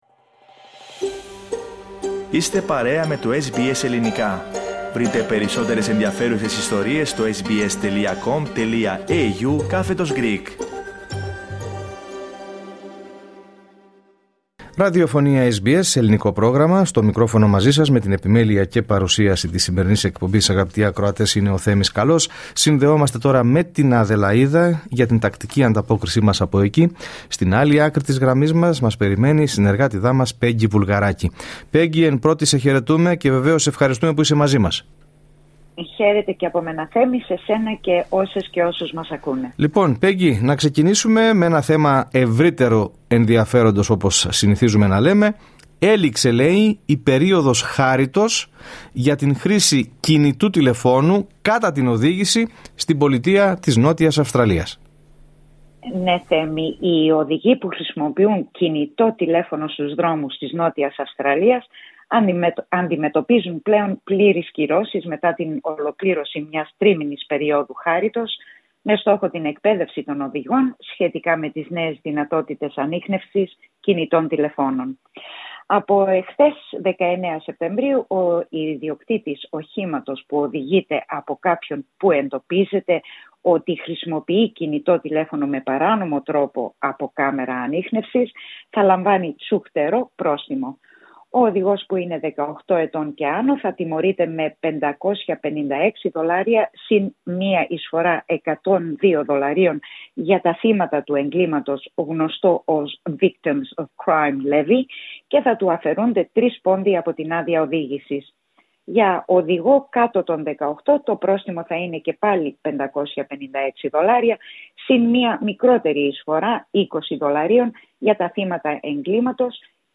Ακούστε την εβδομαδιαία ανταπόκριση από την Αδελαΐδα